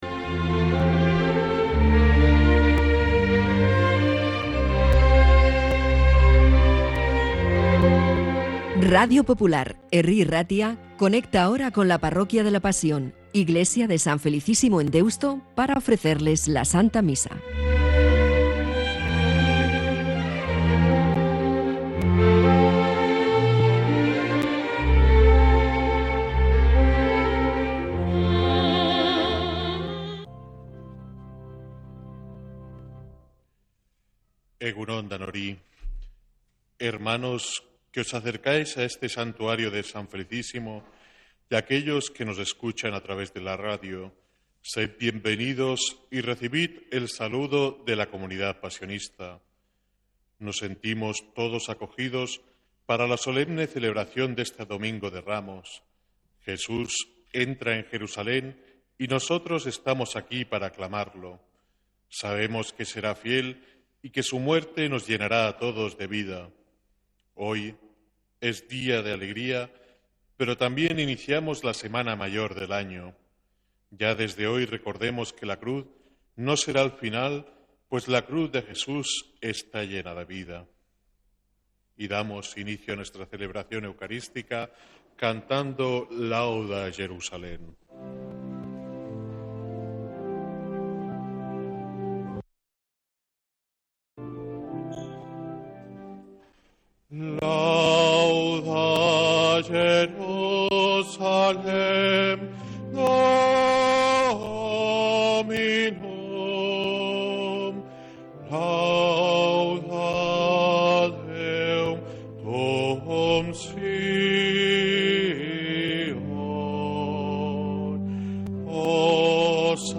Santa Misa desde San Felicísimo en Deusto, domingo 29 de marzo de 2026